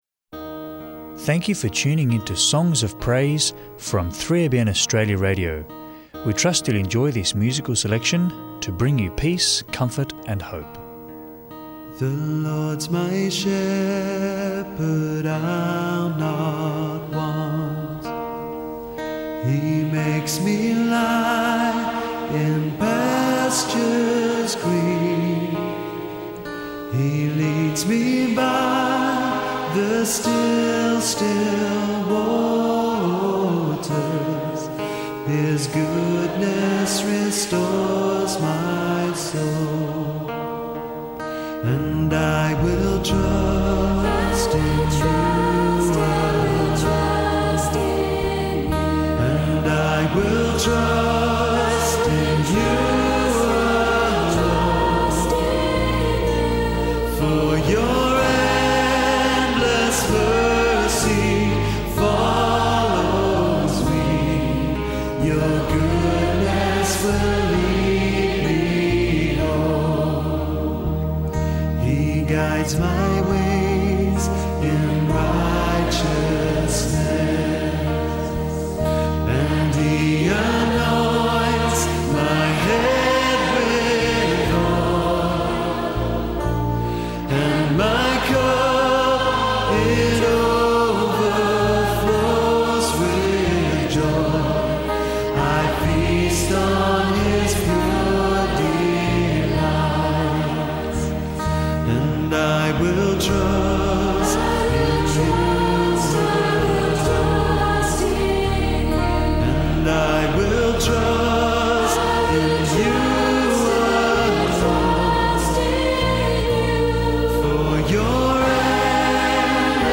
Enjoy uplifting Christian hymns and worship music